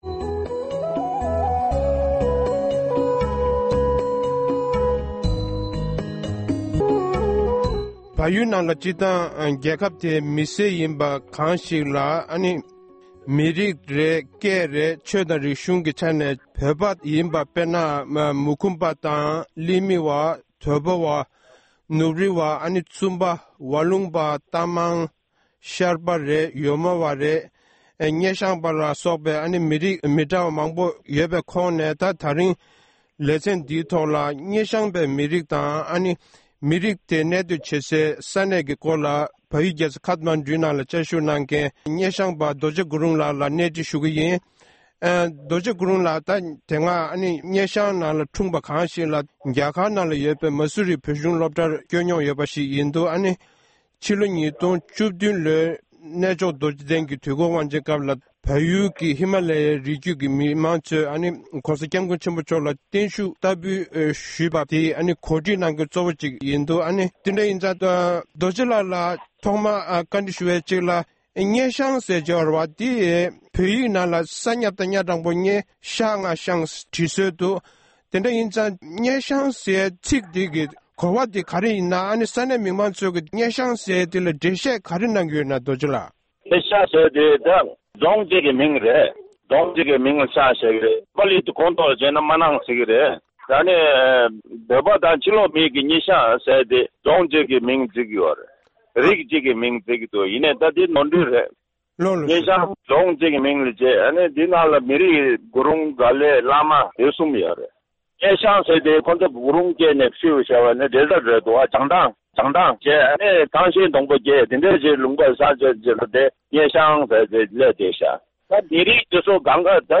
གནས་འདྲི་